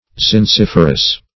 Zinciferous \Zinc*if"er*ous\, a.